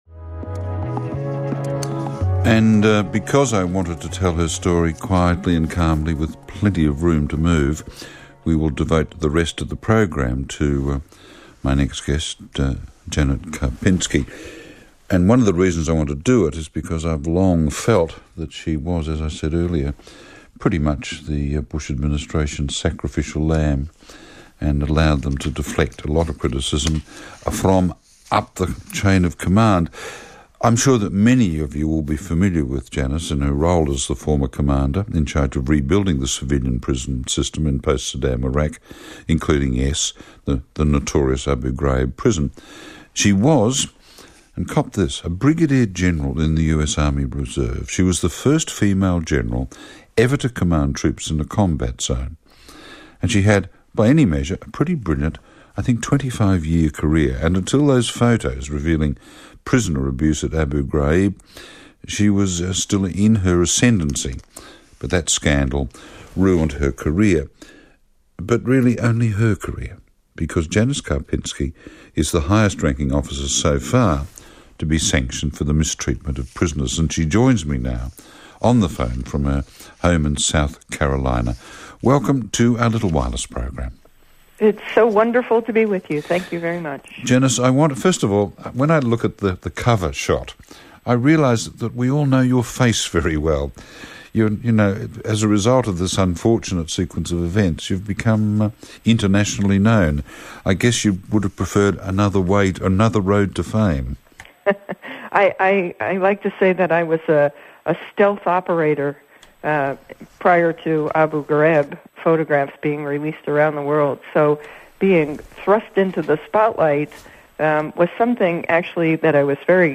A System Of Torture - An Interview With Janis Karpinski About Abu Ghraib - 2006 - Past Daily Reference Room
Janis-Karpinski-interview-Late-Night-Live-2006.mp3